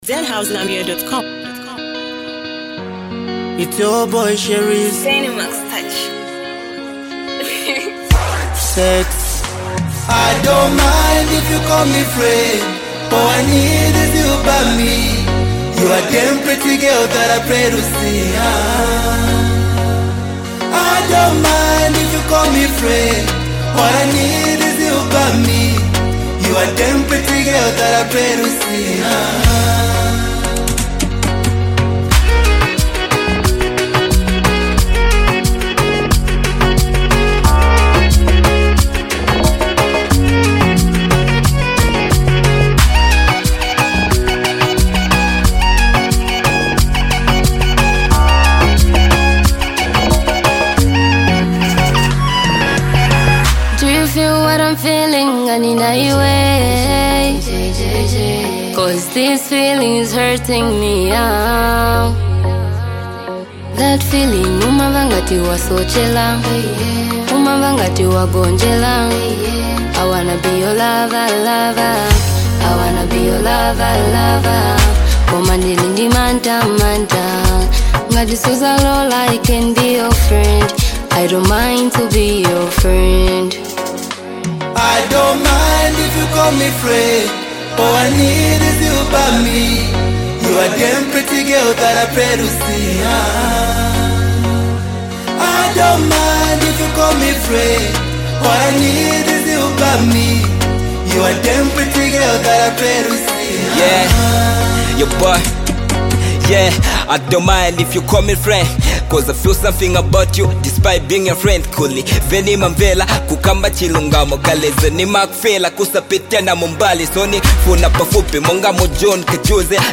a heartfelt song about love, patience, and longing.